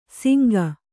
♪ singa